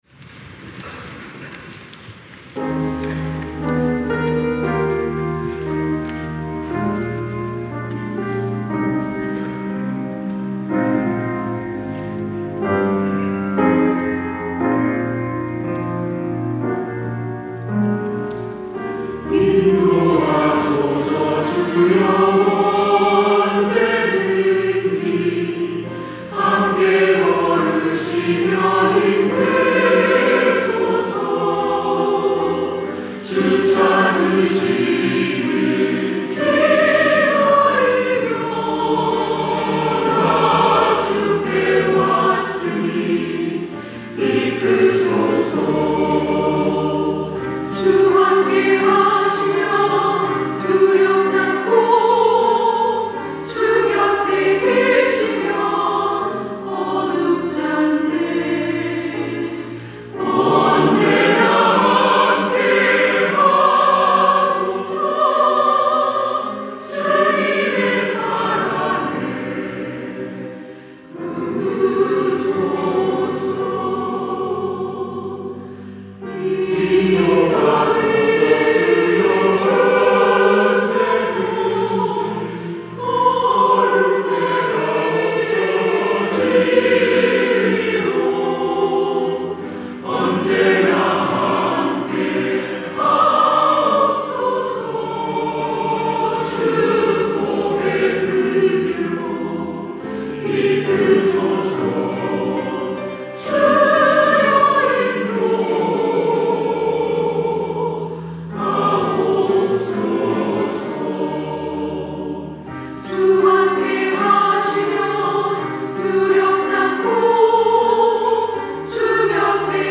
시온(주일1부) - 주여 인도하소서
찬양대